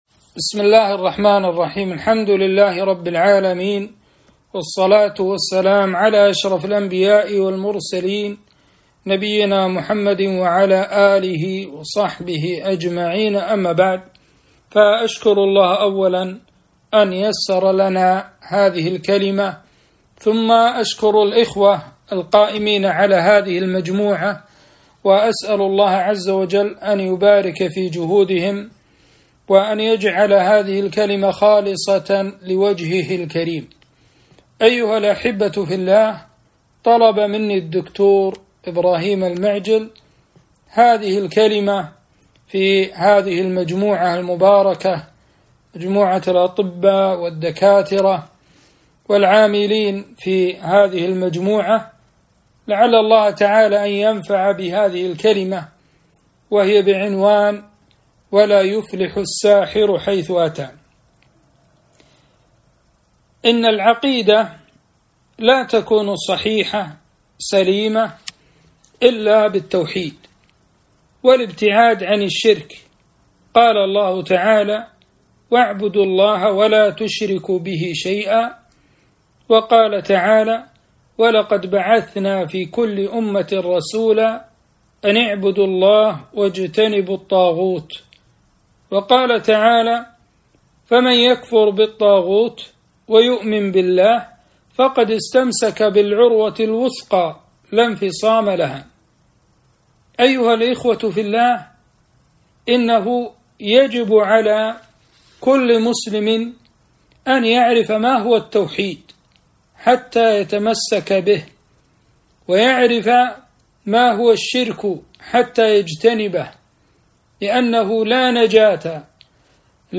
محاضرة - التحذير من السحر والشعوذه